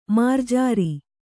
♪ mārjāri